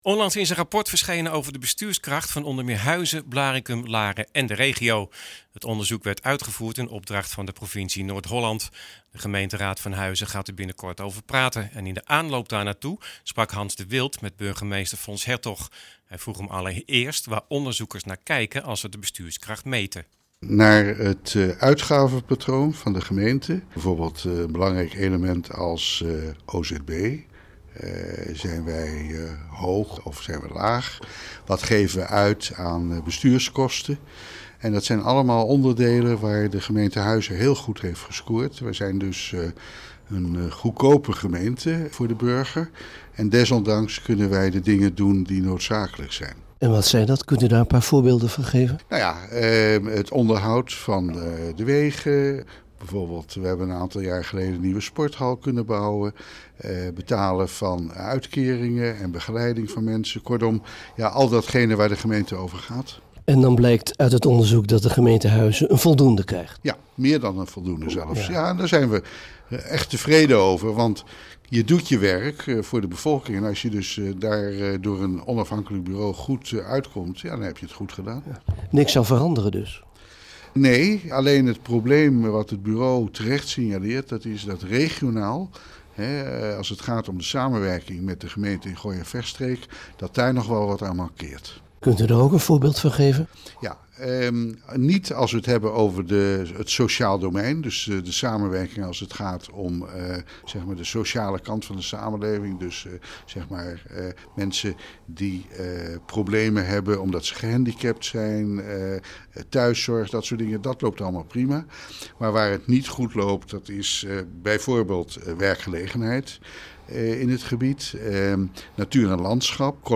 Burgemeester Fons Hertog over het verschenen rapport over de bestuurskrachtmeting van Huizen.